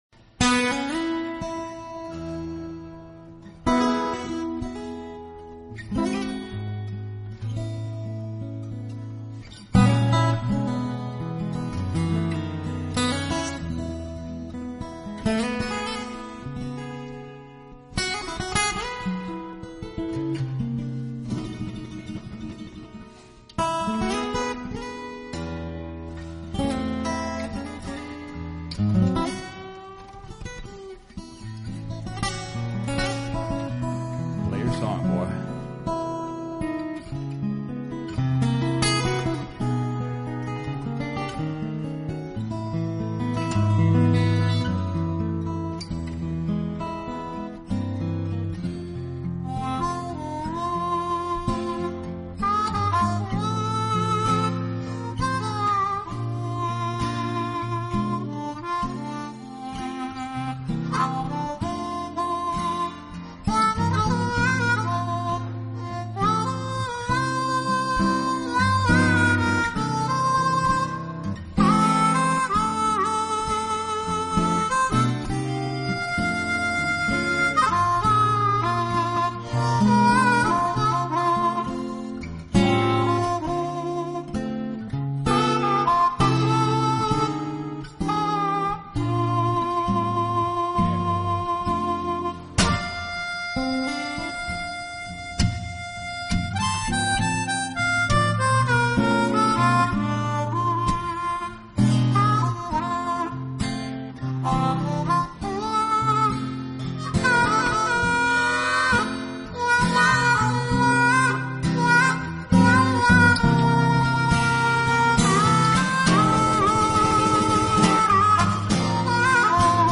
【吉他专辑】
音乐类型：Instrumental